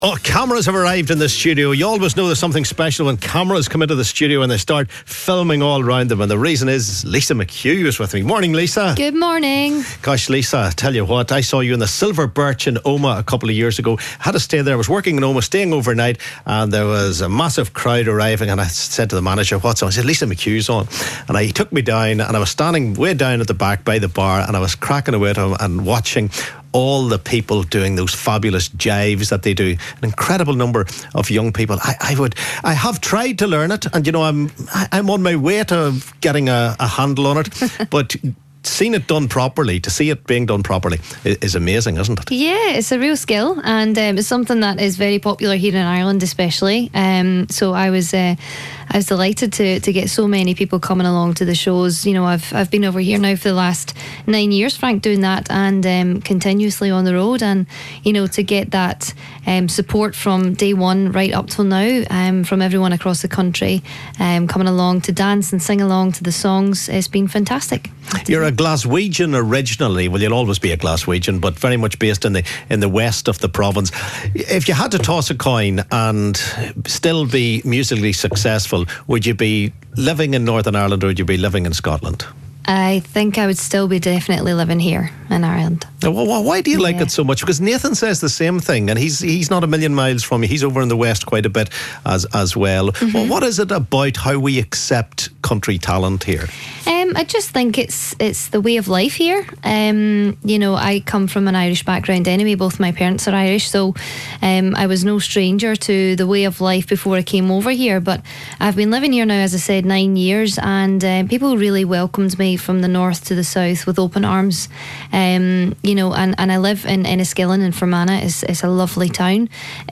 Country singer